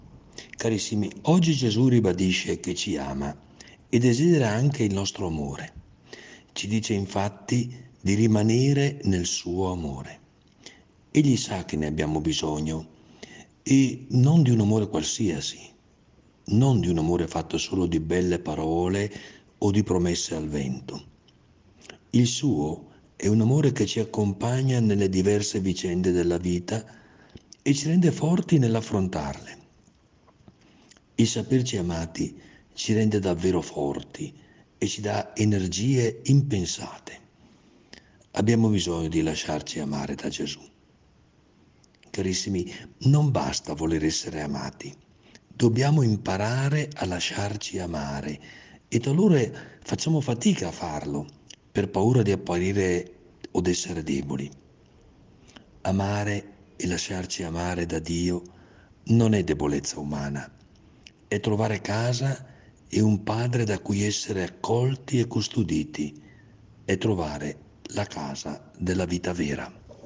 Il Vescovo commenta la Parola di Dio per trarne ispirazione per la giornata.